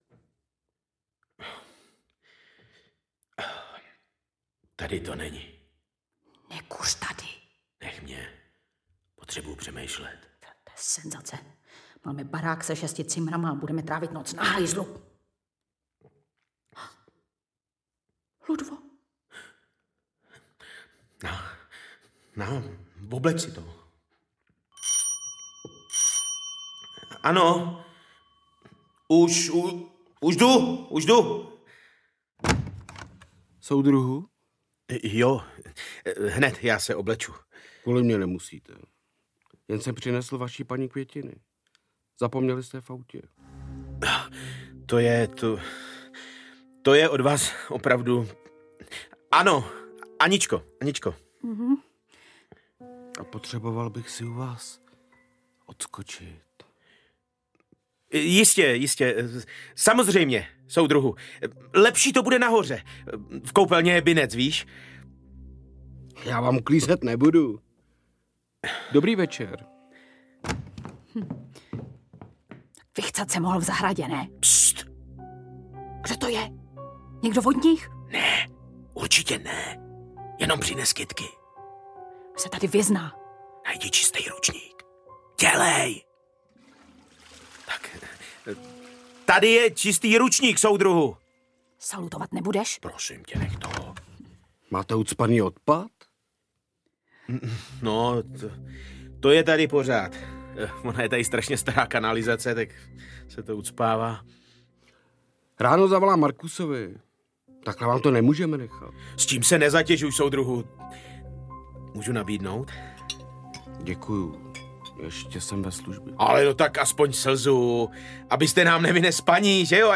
Ucho audiokniha
Ukázka z knihy
• InterpretLucie Vondráčková, Milan Enčev